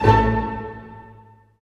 Index of /90_sSampleCDs/Roland L-CD702/VOL-1/HIT_Dynamic Orch/HIT_Staccato Oct